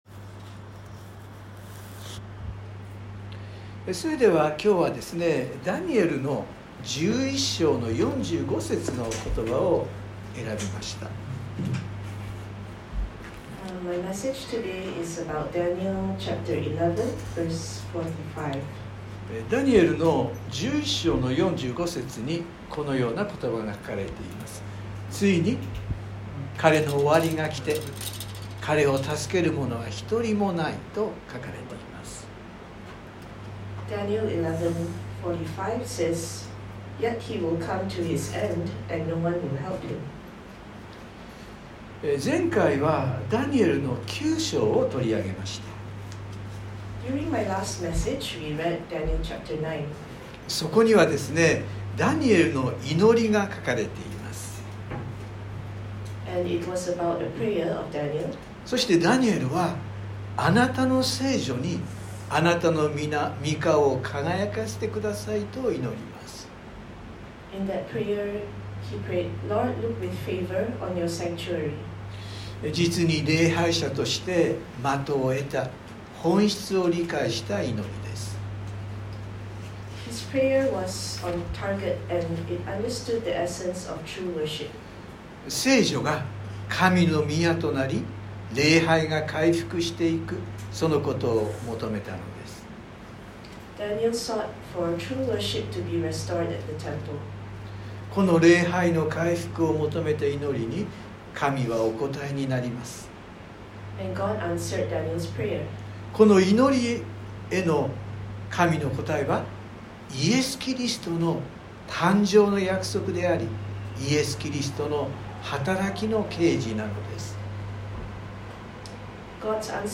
（日曜礼拝録音） 【iPhoneで聞けない方はiOSのアップデートをして下さい】 前回は、ダニエル書9章を取り上げました。